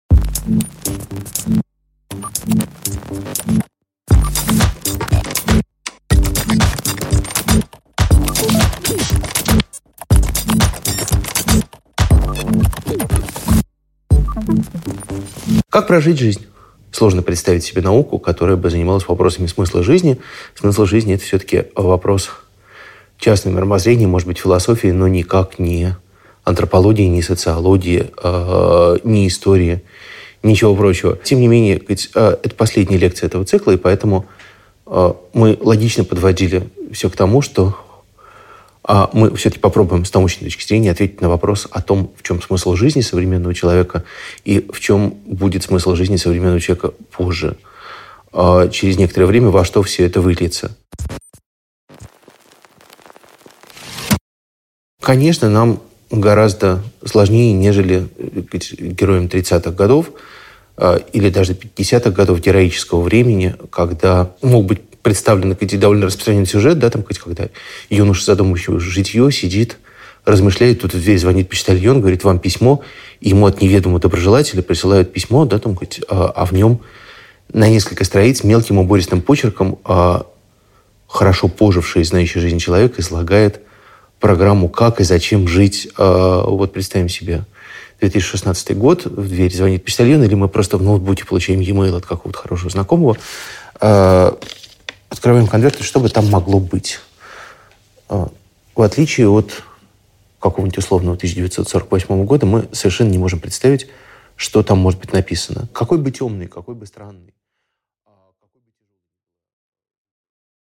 Аудиокнига Открытка с пожеланиями: новый идеал судьбы | Библиотека аудиокниг